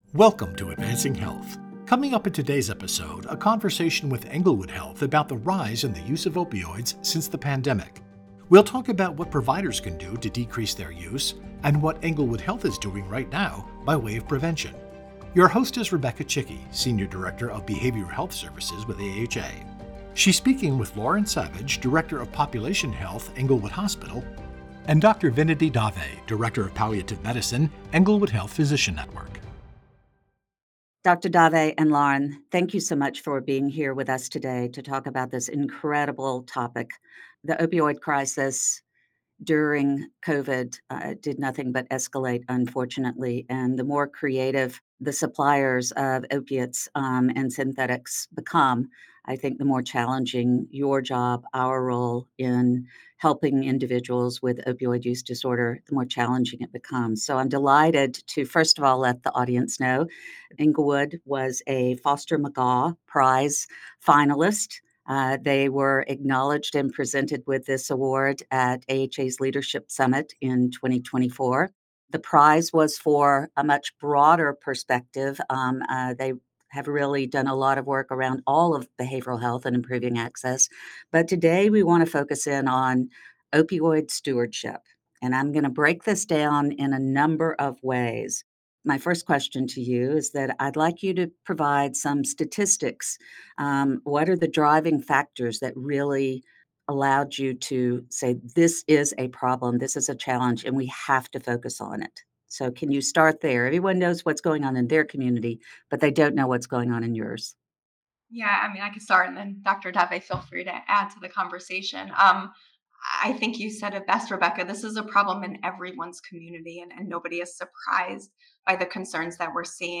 Coming up in today's episode, a conversation with Englewood Health about the rise in the use of opioids since the pandemic.